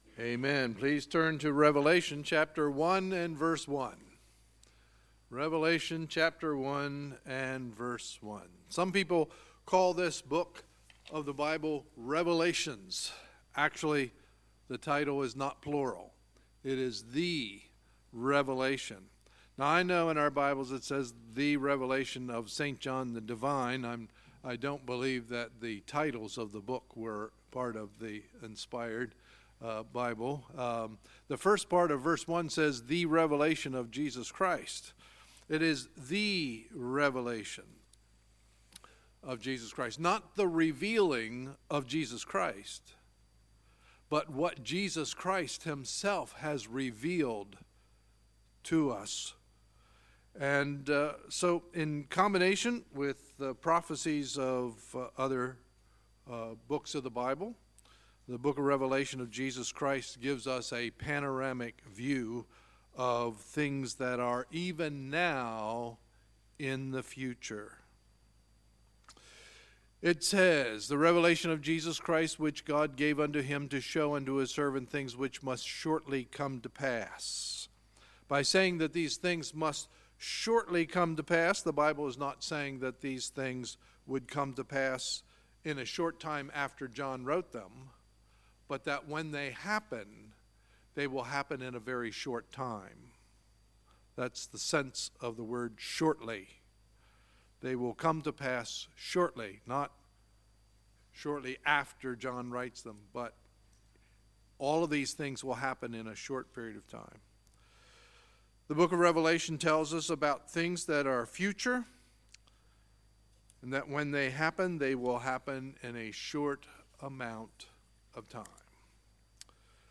Sunday, January 28, 2018 – Sunday Evening Service